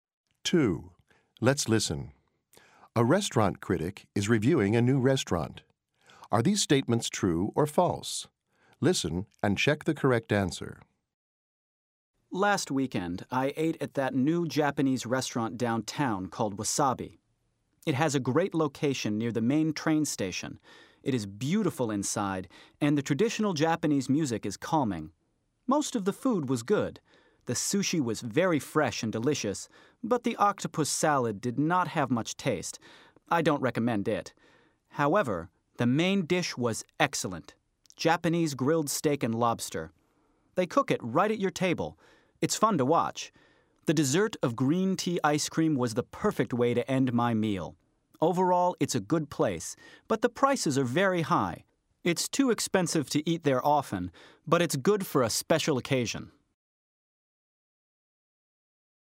A restaurant critic is reviewing a new restaurant.